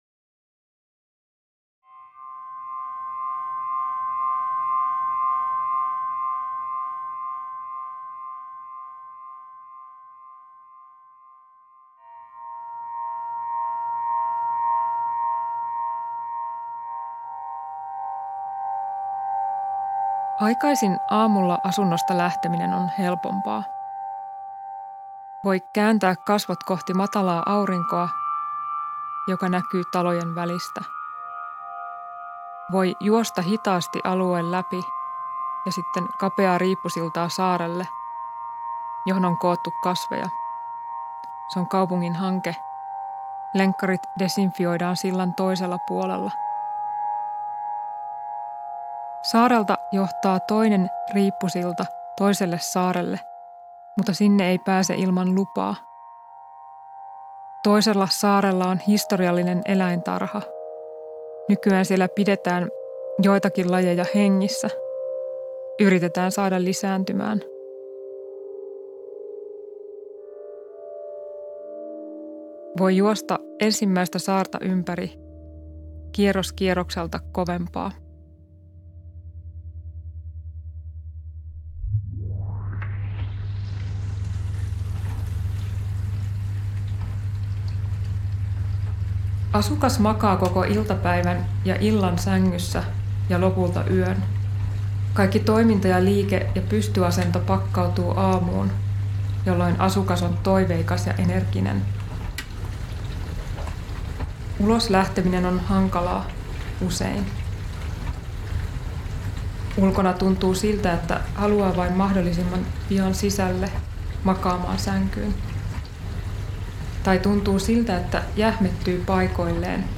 Kuuntele AU: Luku 1 -ääniteos: